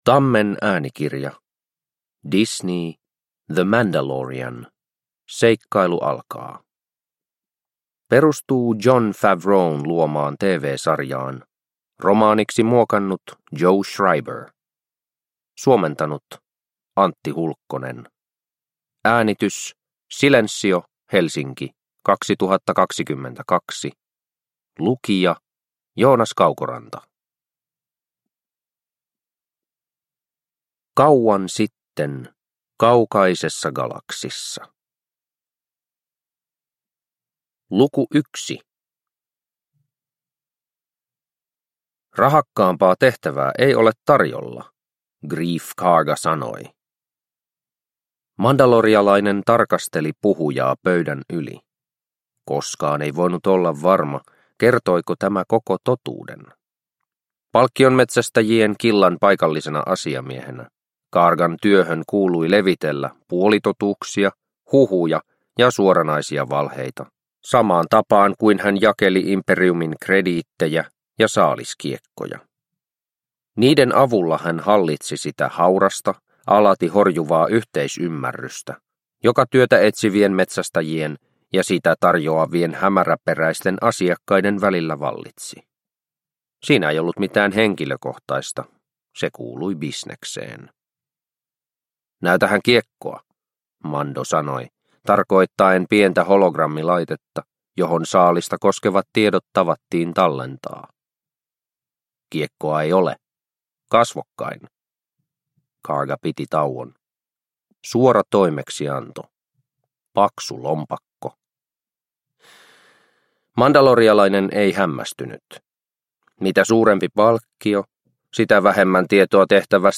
The Mandalorian - seikkailu alkaa – Ljudbok – Laddas ner